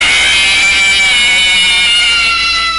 jumpscare.mp3